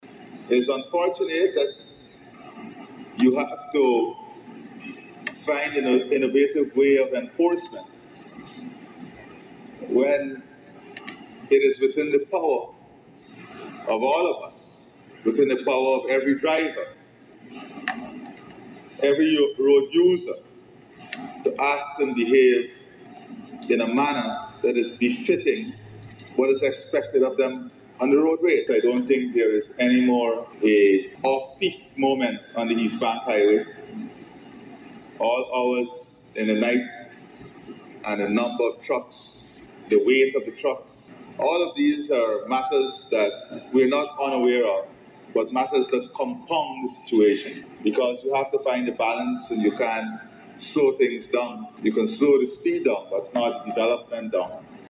Speaking at the opening of the Police Officers’ three-day annual conference held at Police HQ, Eve Leary, President Ali emphasized the importance of strategic investments and developments to enhance the GPF’s capacity.